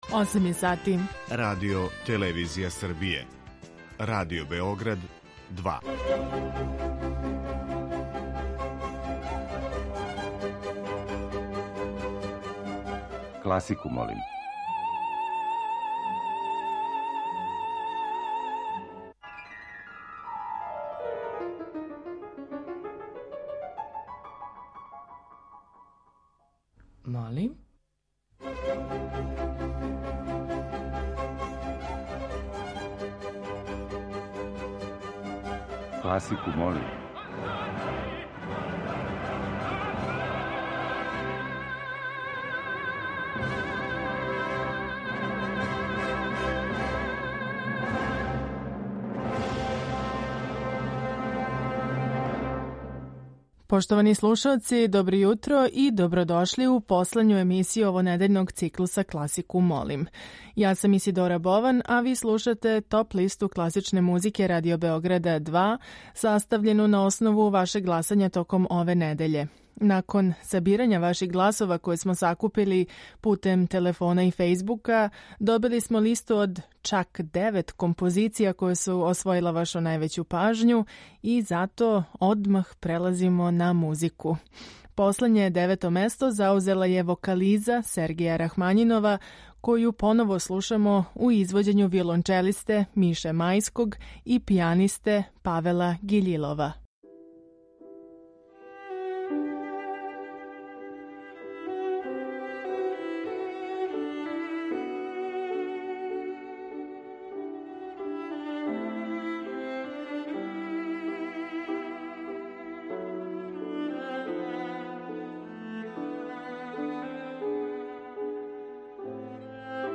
Топ-листа класичне музике